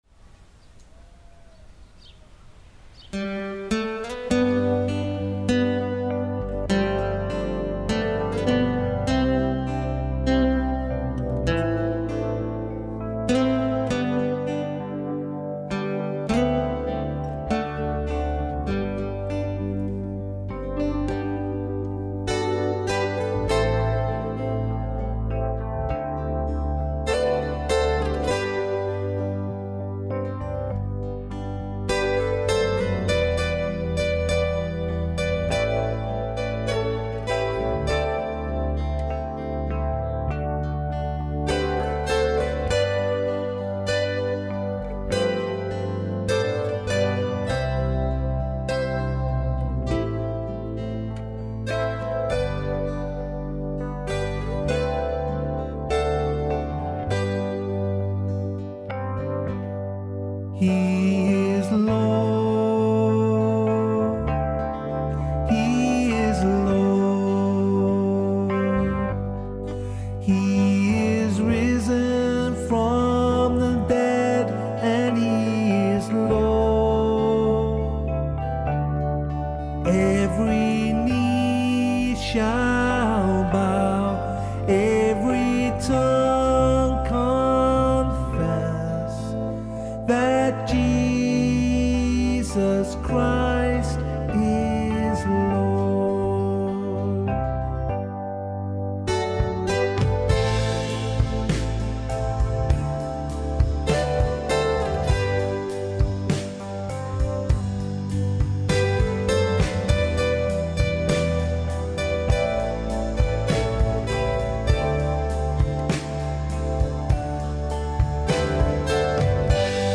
Worship-4-Apr-2021.mp3